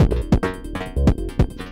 Tag: 140 bpm Electronic Loops Vocal Loops 295.49 KB wav Key : G